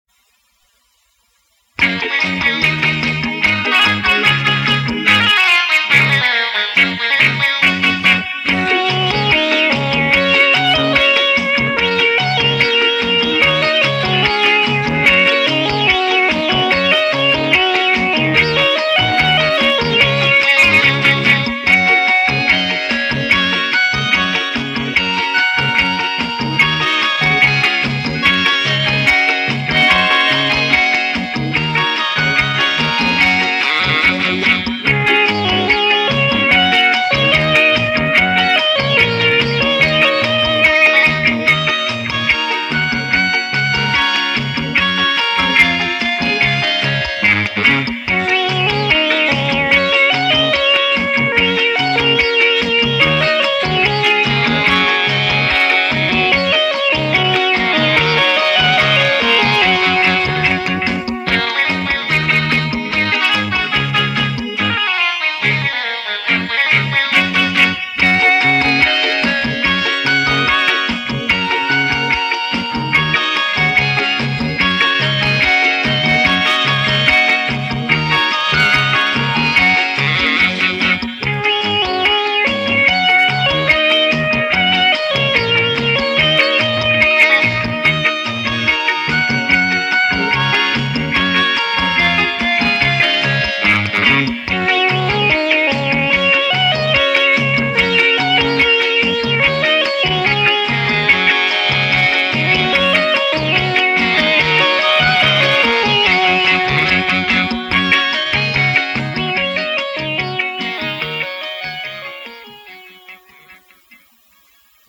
Cha Cha